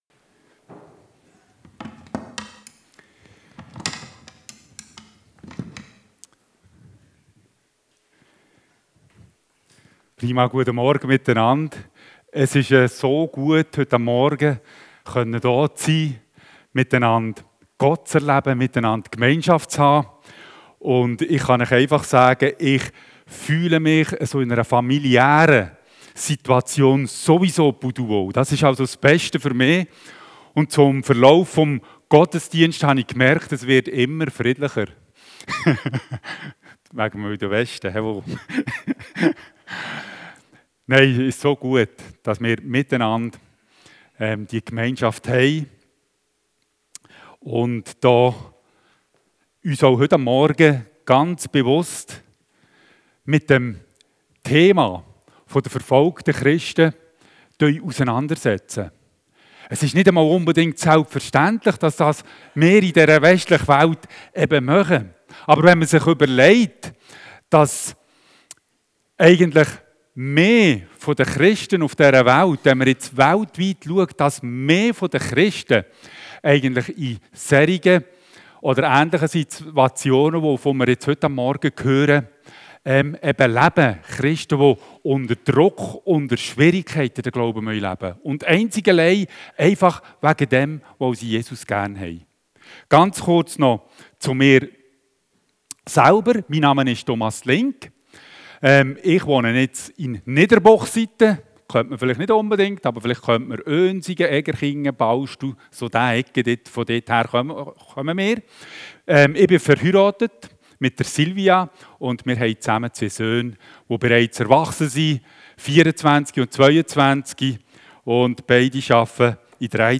Missions-Gottesdienst mit AVC